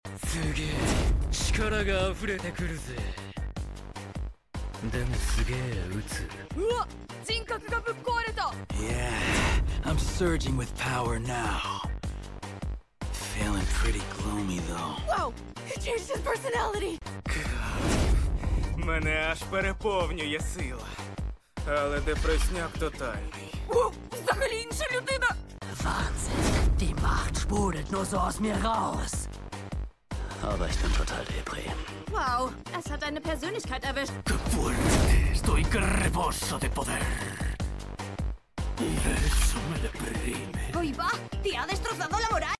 Okarun Mp3 Sound Effect What's the best voice-over? Okarun uses the power of Turbo Granny in different languages.